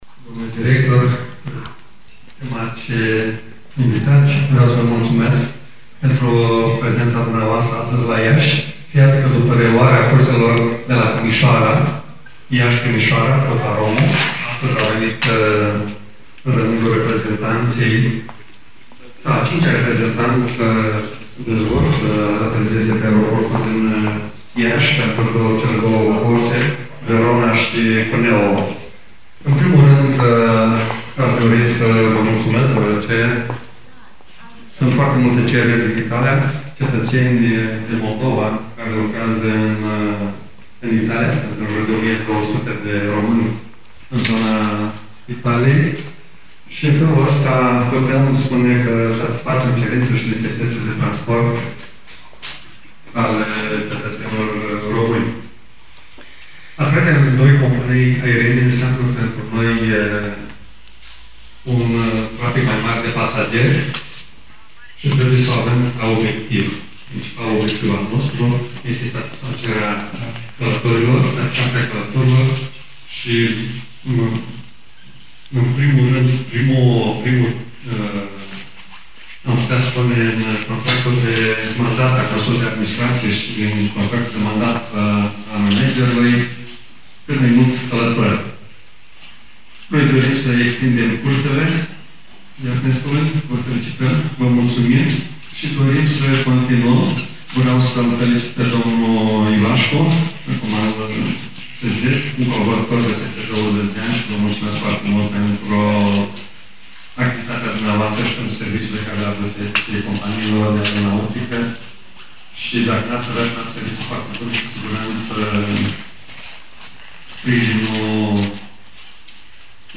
Ascultă AICI integral discursul președintelui Consiliului Județean Iași, Maricel Popa